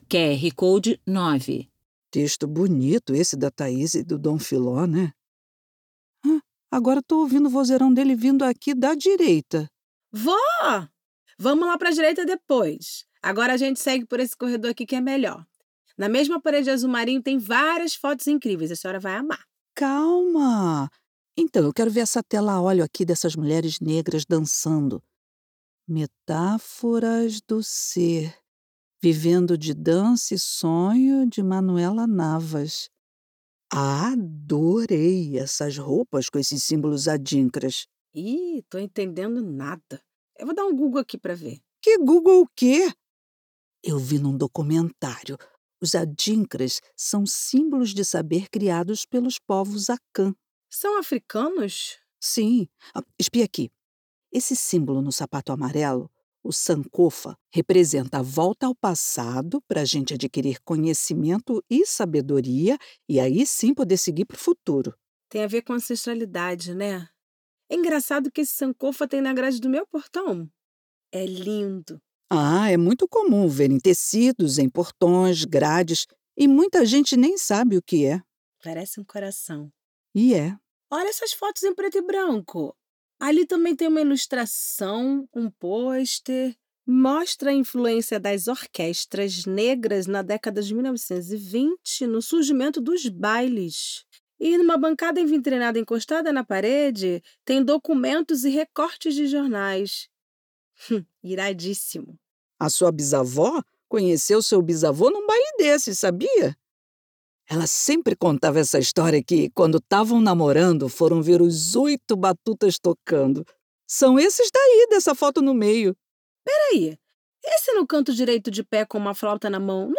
Audiodescrição